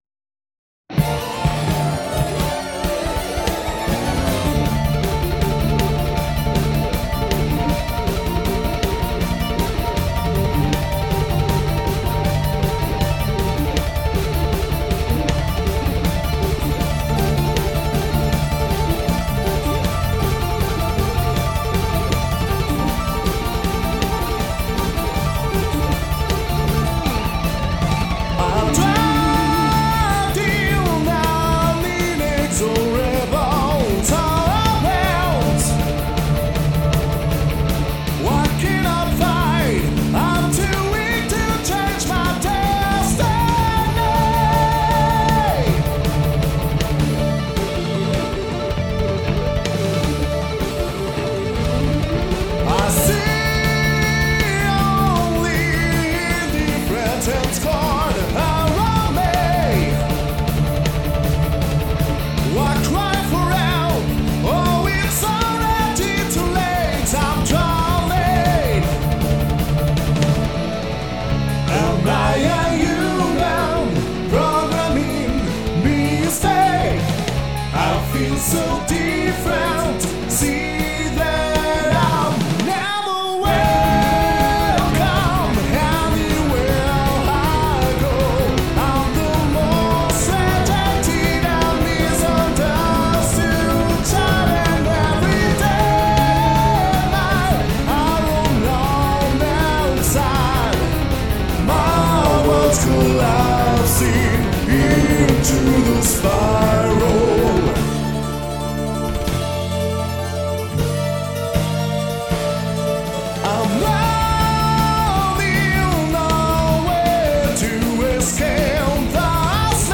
Maelstorm est un groupe de metal aux influences très diverses.
Bon ben ça sonne très bien tout ça, il faudra que je retente le coup un jours!